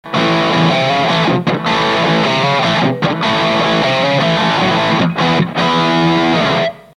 Guitar Fender STRTOCASTER
Amplifier VOX AD30VT AC15
GAIN全開VOLUME全開